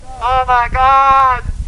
The next Wilhelm Scream?